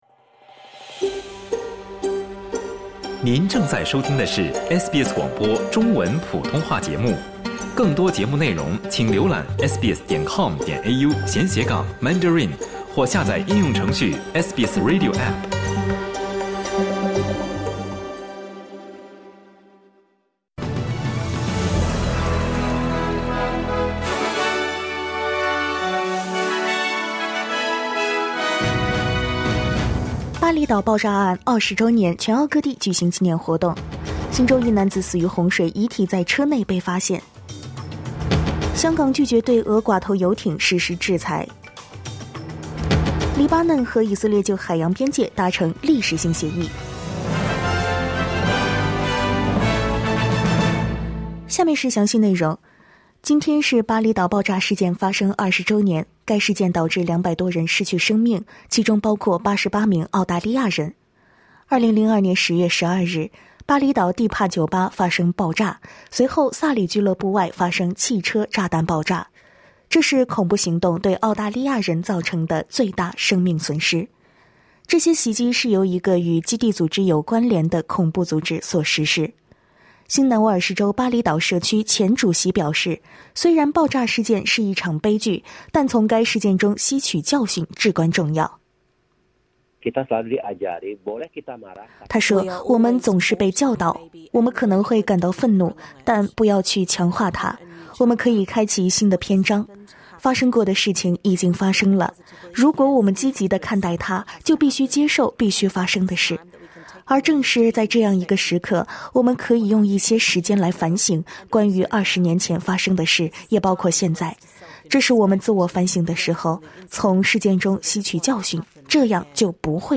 SBS早新闻（10月12日）